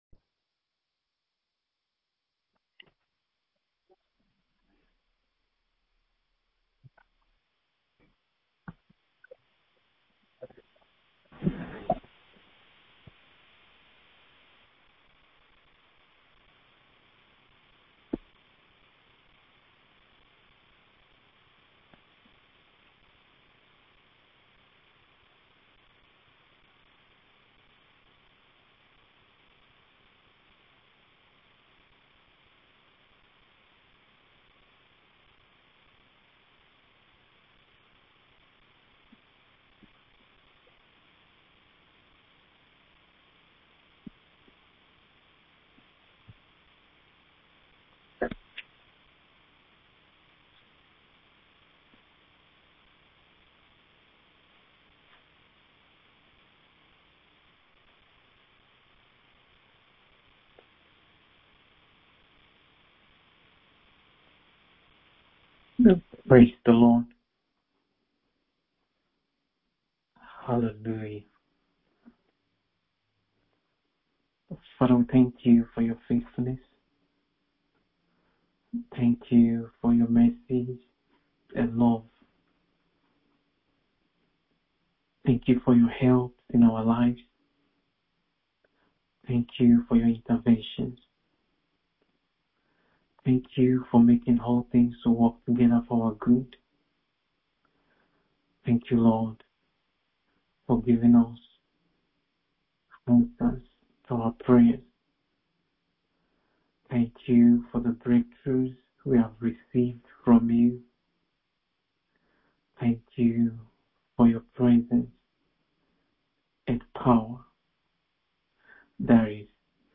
MIDNIGHT PRAYER SESSION : 15 MARCH 2025